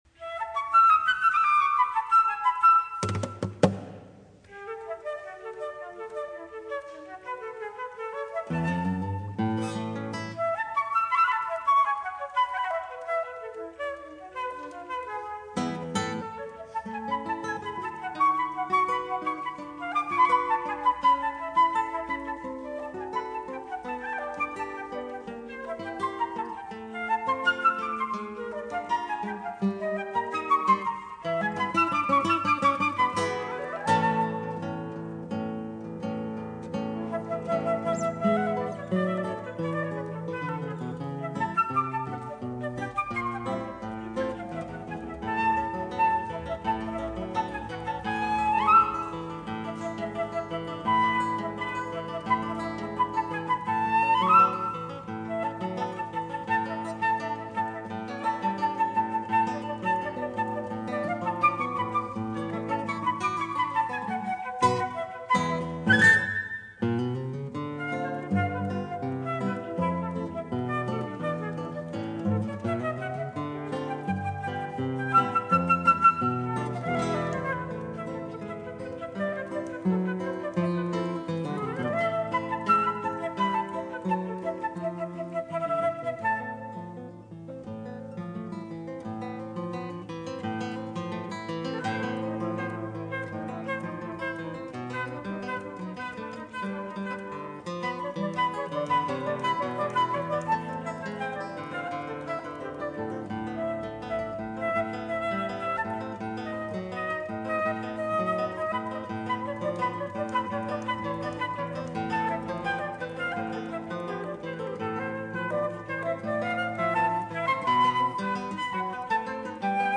flauto
chitarra